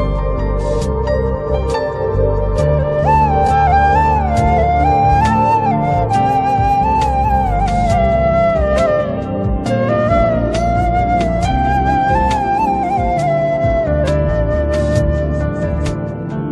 Is a ringtone played in flute